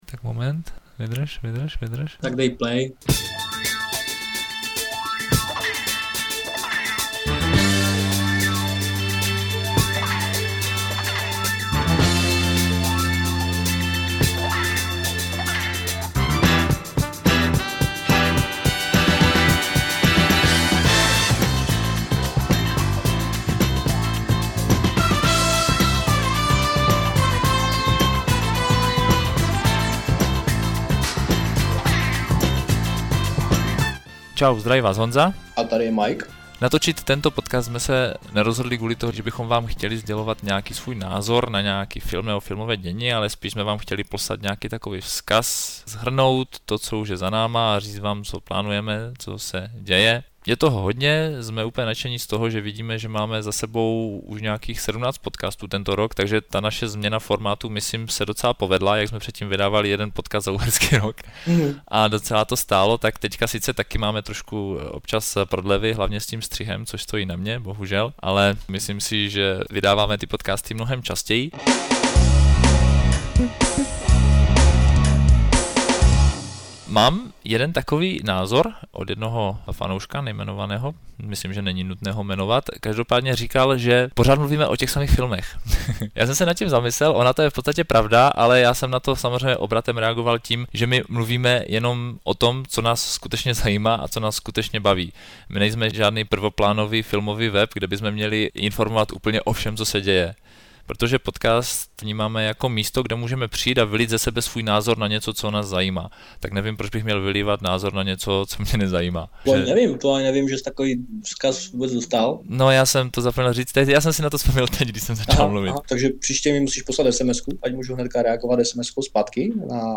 Vše proběhlo bez přípravy ve freestyle stylu